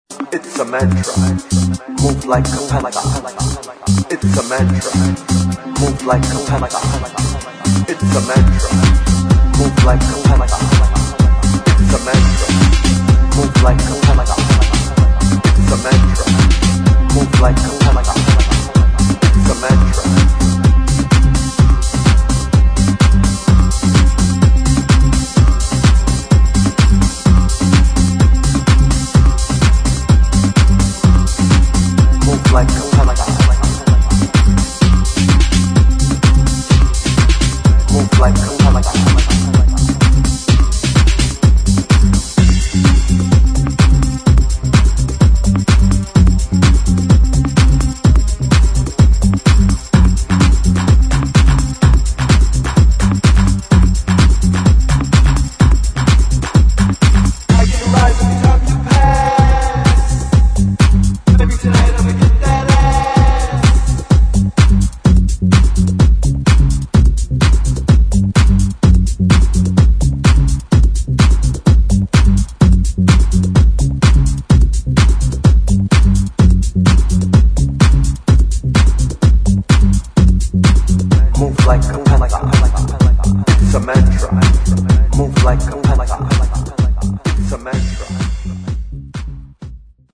[ HOUSE | TECHNO ]